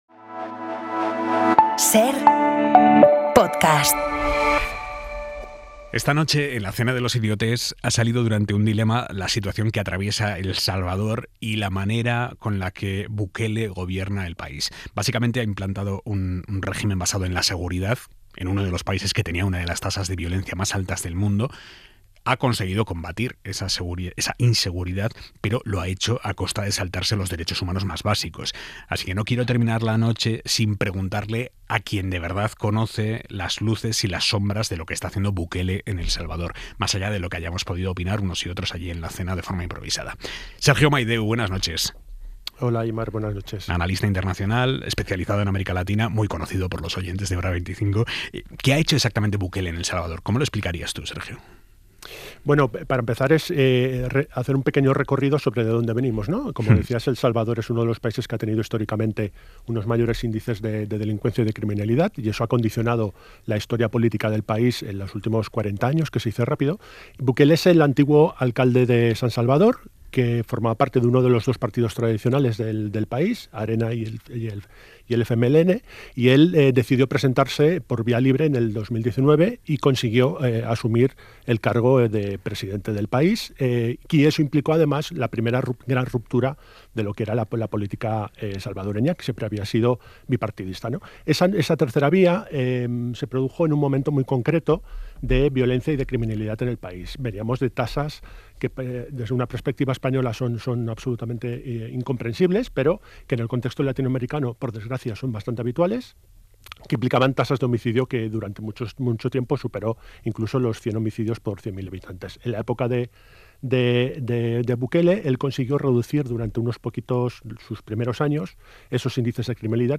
La Entrevista | Un analista explica las sombras del régimen autoritario de Bukele en El Salvador